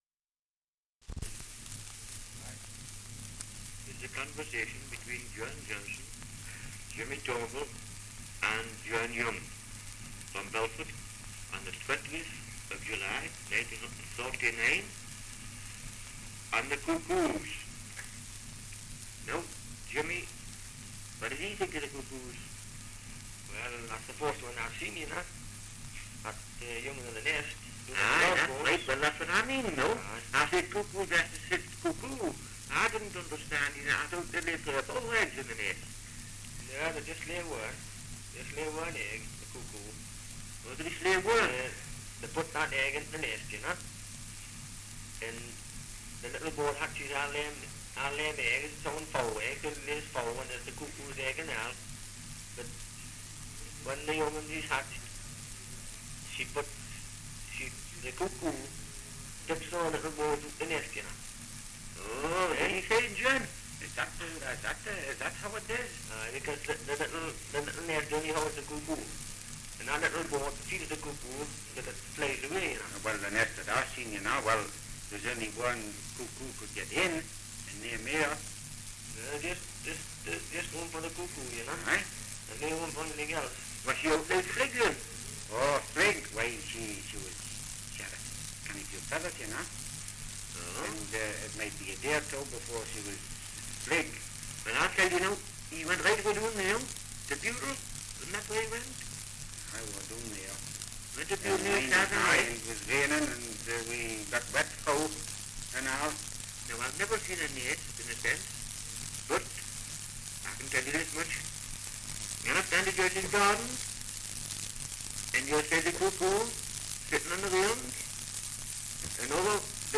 1 - Dialect recording in Belford, Northumberland
78 r.p.m., cellulose nitrate on aluminium